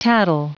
Prononciation du mot tattle en anglais (fichier audio)
Prononciation du mot : tattle